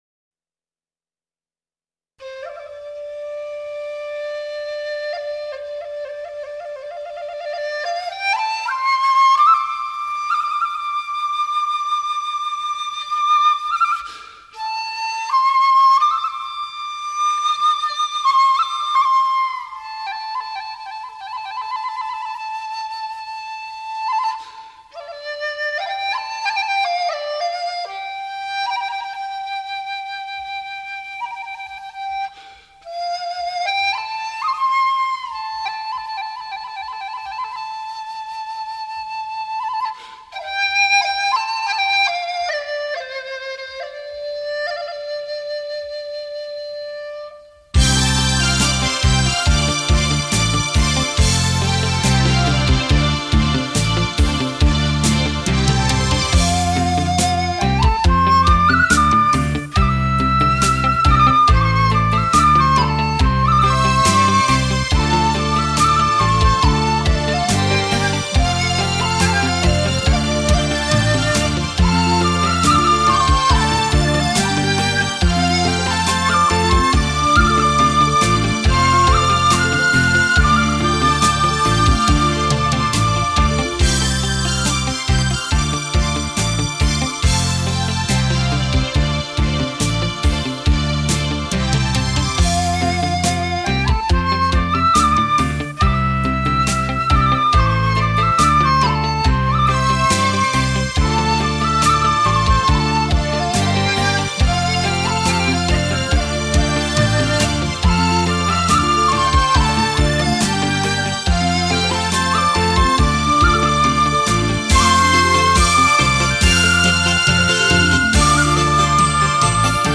音乐类型: 民乐
嘹亮清脆的电子琴音乐遇上音色圆润，清新优美的笛子。